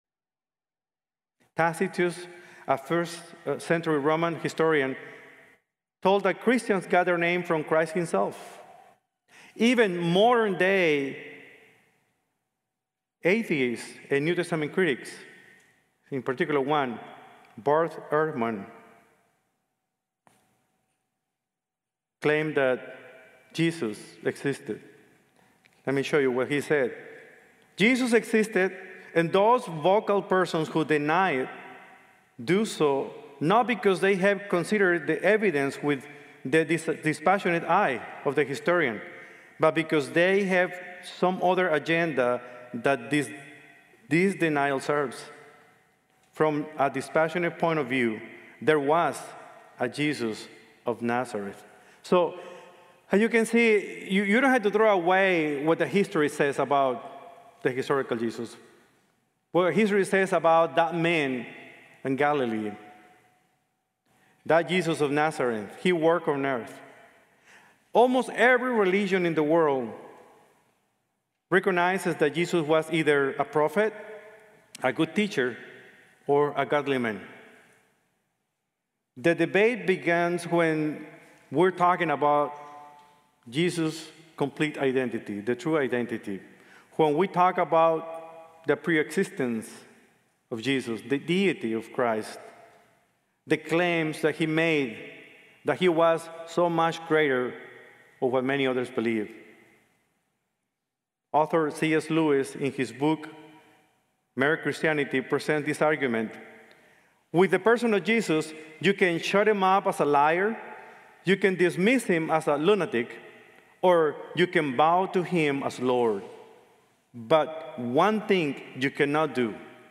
Christology: God Incarnated | Sermon | Grace Bible Church